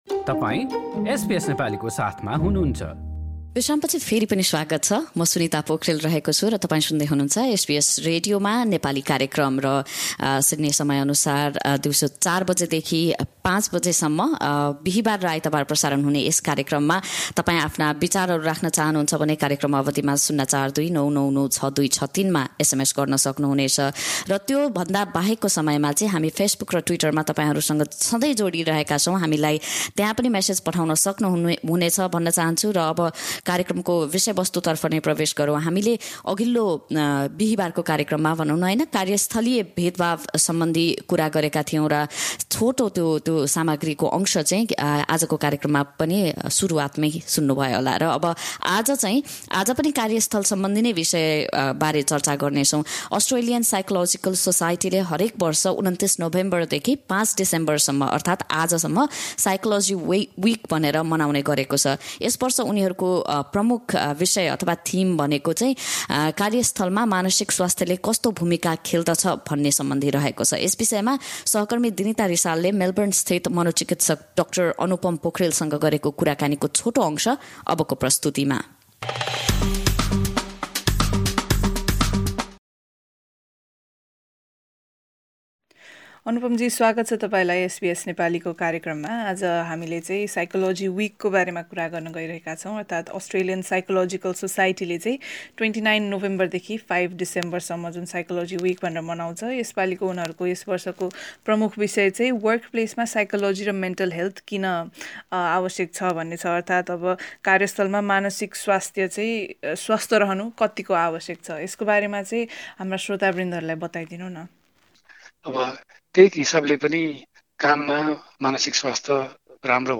कार्यस्थल र मानसिक स्वास्थ्यको विषयलाई मध्यनजर गर्दै गरेको कुराकानी।